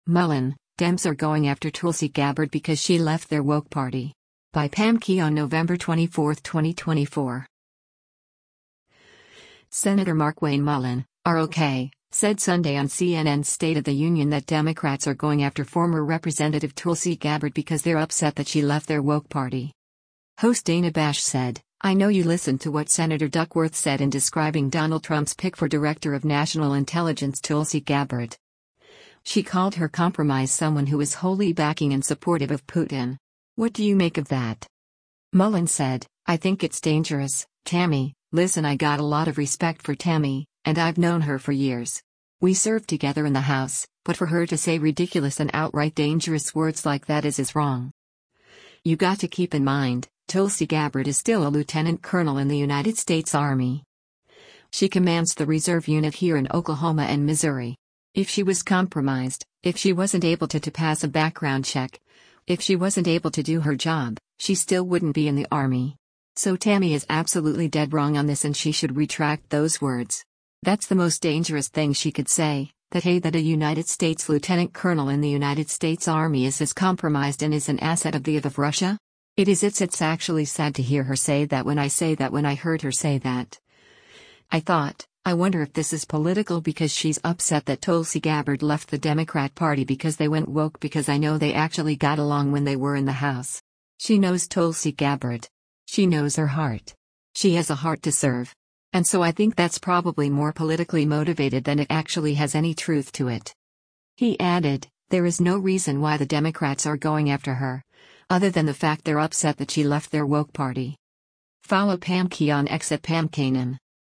Senator Markwayne Mullin (R-OK) said Sunday on CNN’s “State of the Union” that “Democrats are going after” former Rep. Tulsi Gabbard because “they’re upset that she left their woke party.”